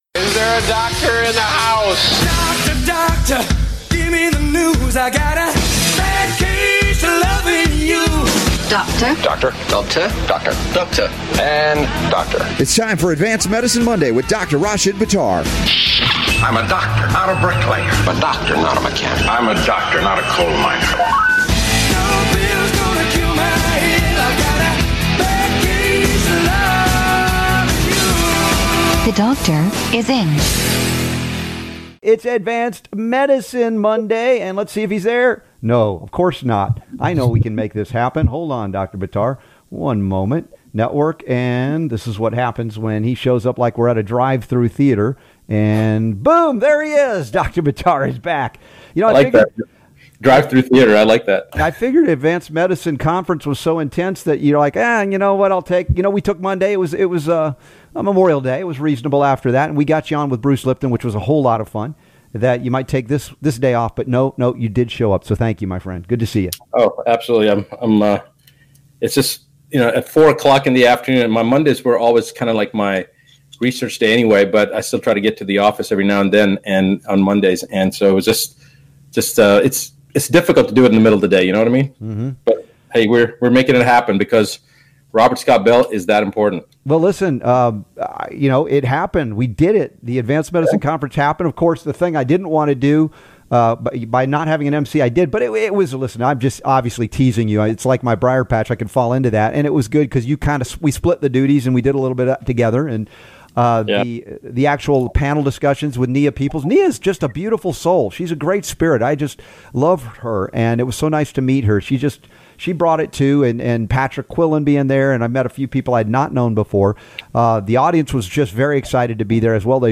Advanced Medicine Radio Show | 6-3-2019 Get ready to learn things not traditionally taught to medical doctors!